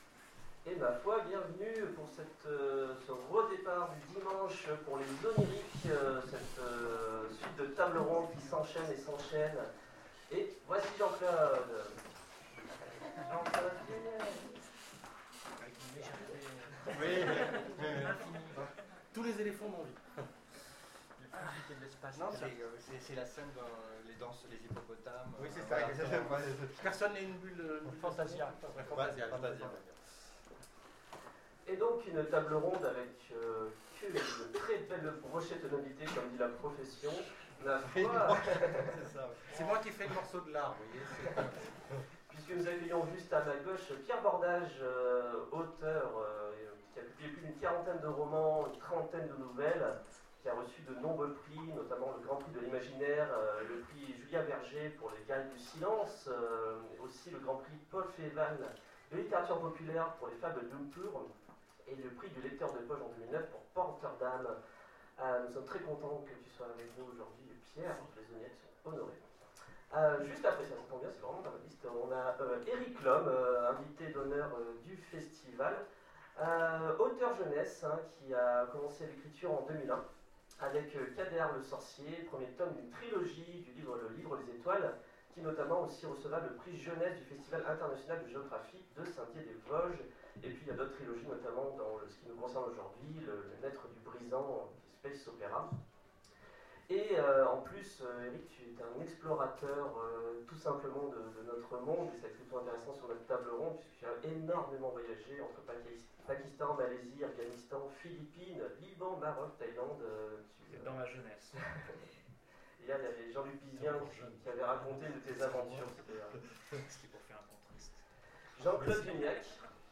Les Oniriques 2015 : Table ronde Décrocher la lune...